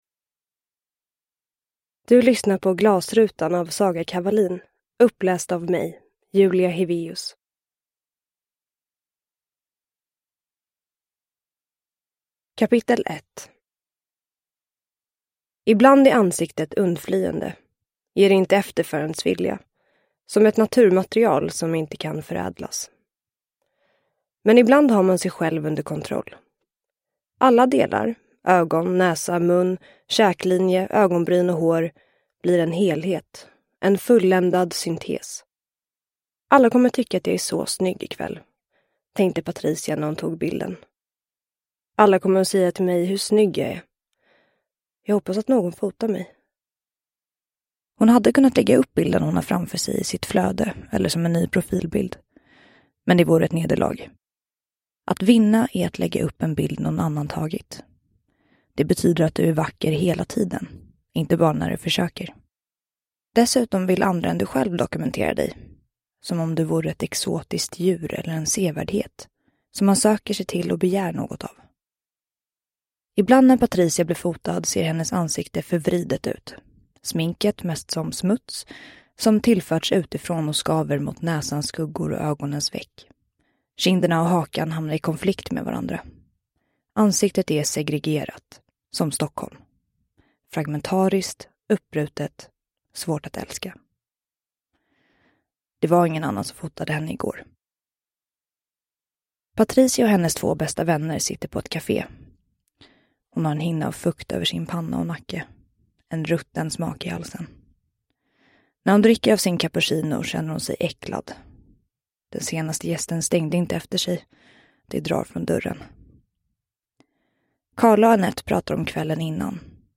Glasrutan – Ljudbok – Laddas ner